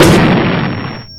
HHGboom.mp3